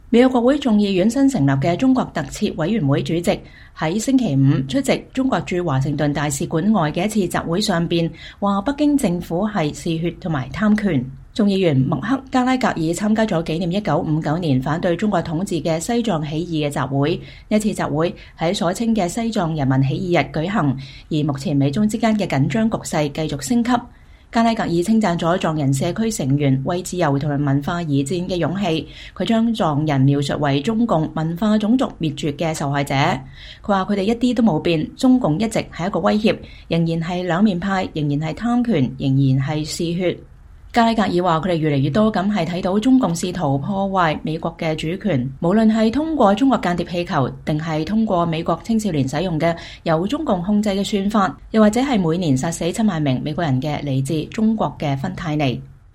週五(3月10日)，在中國駐華盛頓大使館外的一次集會上，美國國會眾議院新成立的中國特設委員會主席稱北京政府“嗜血”和“貪權”。